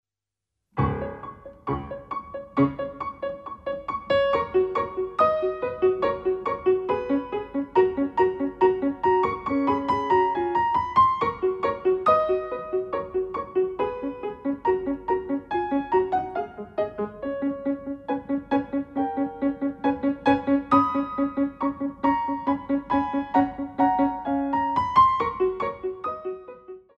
Ballet Class Music For Children aged 5+